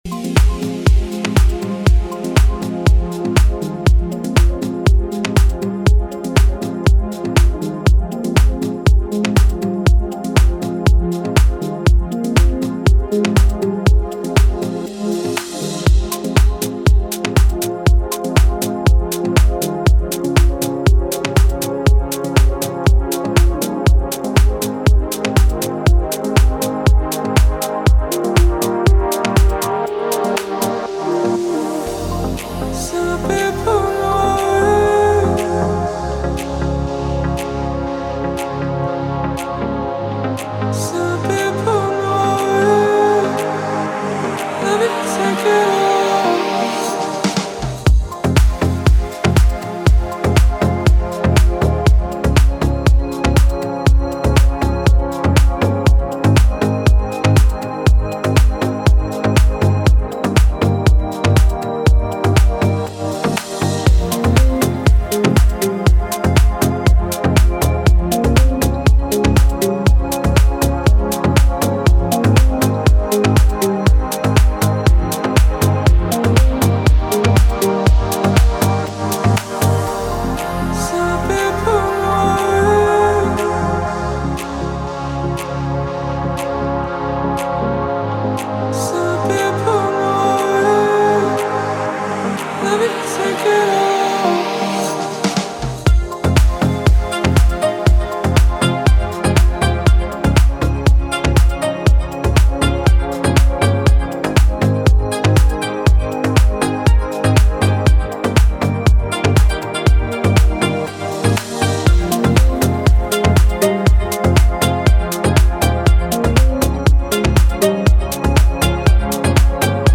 это энергичная композиция в жанре поп-музыки